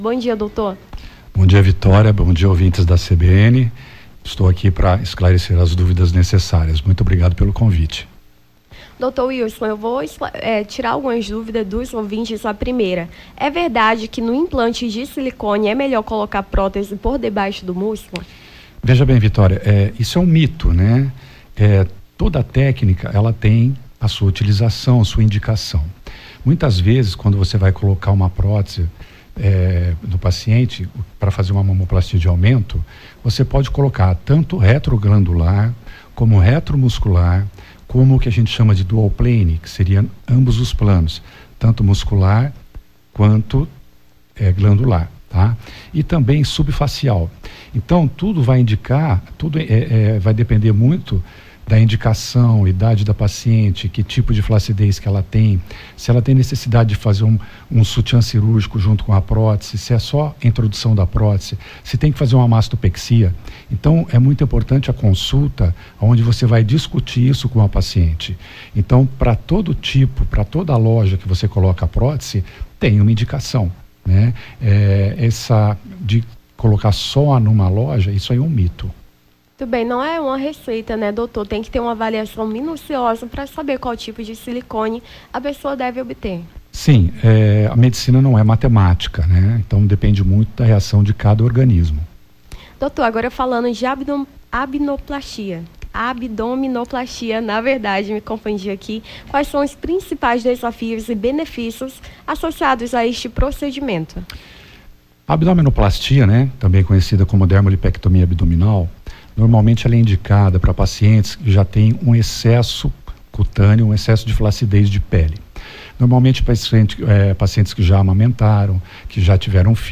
Nome do Artista - CENSURA - ENTREVISTA (BOM DIA DOUTOR) 28-09-23.mp3